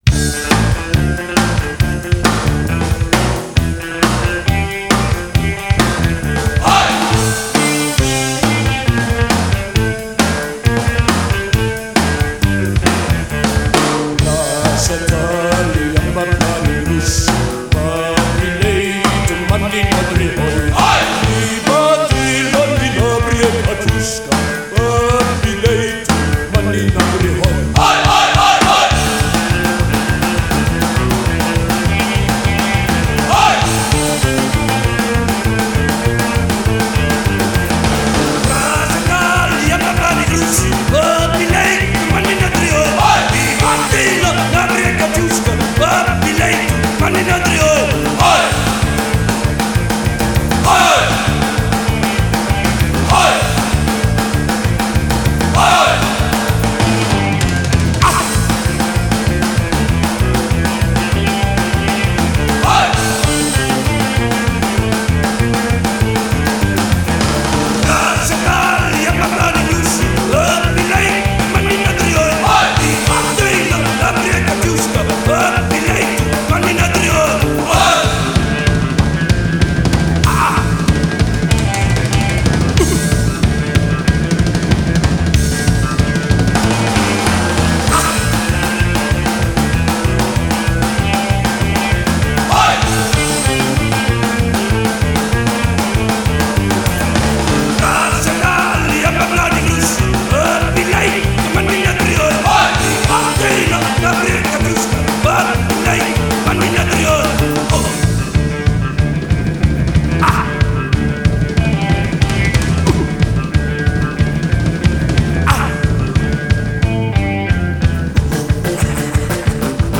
Жанр: Rock n Roll